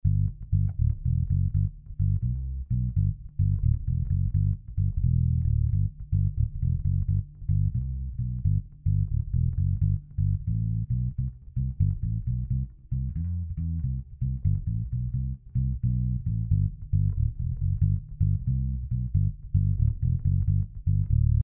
Téma: Baskytara FOKUS V-950 Active
Ale vyměnil jsem snímače, aktivní elektroniku vyhodil a osadil basu jako pasivku a je to v pohodě.
A tak to dnes hraje: